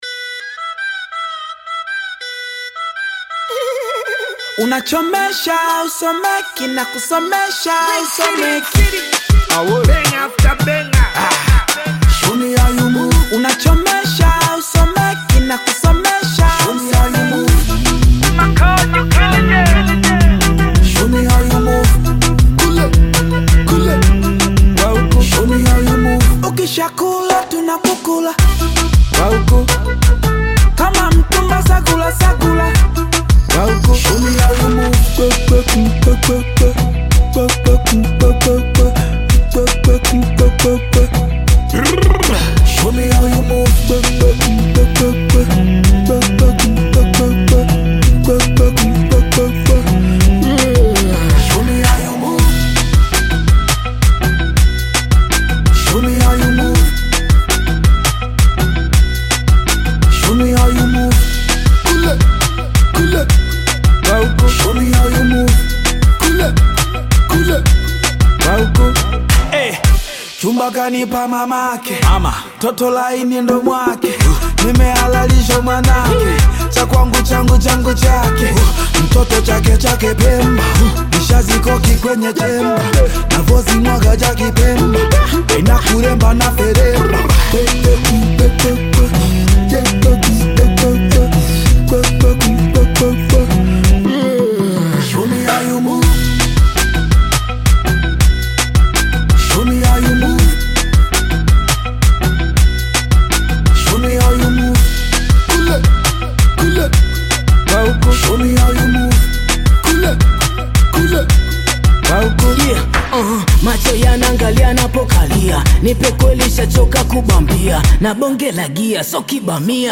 Another song from bongo flava artist